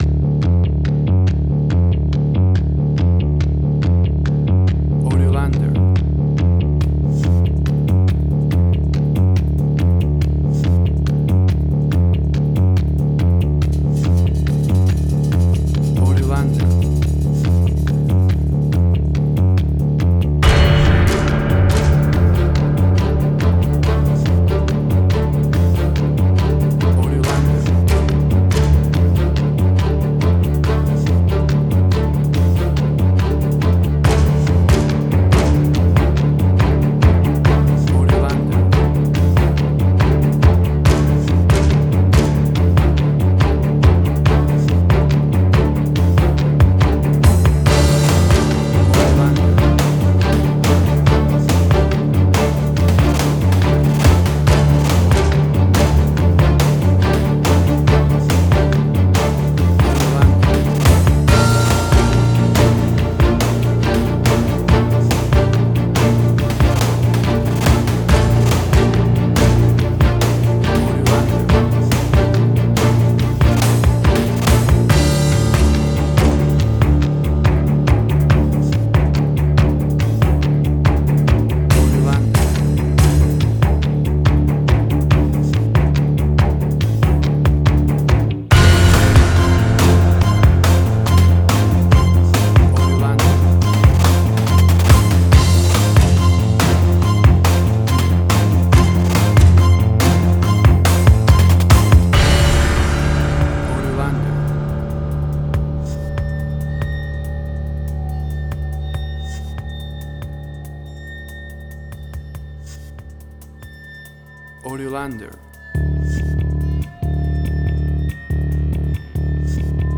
Suspense, Drama, Quirky, Emotional.
Tempo (BPM): 141